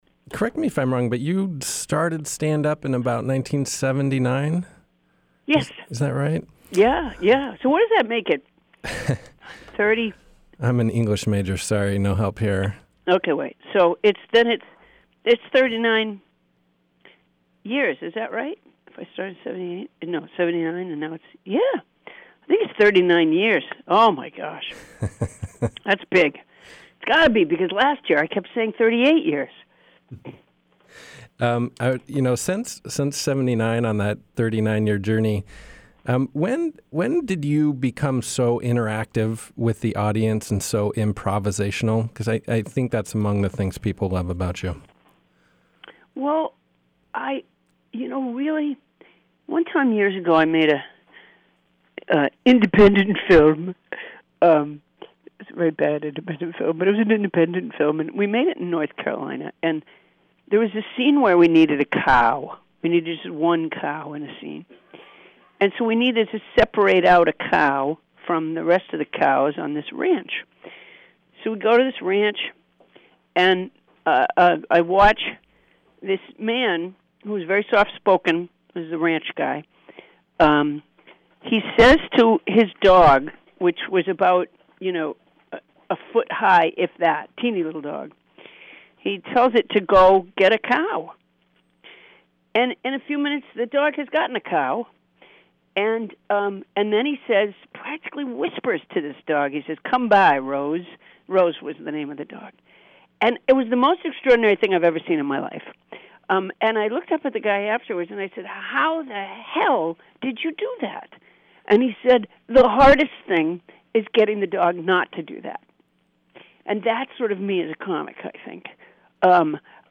Interview: Paula Poundstone talks beaver dens, improv and using her audience
I gave her a call and we talked about beaver dens, “Wait Wait … Don’t Tell Me!” and more.